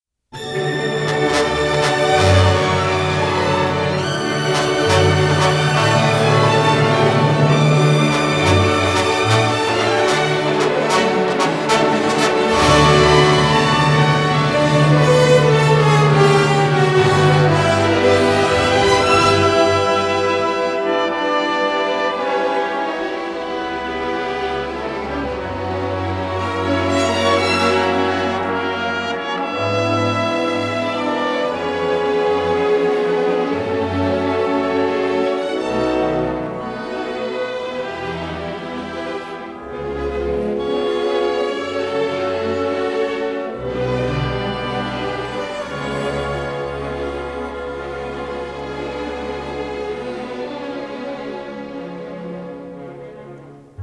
Stereo recording made in February 1960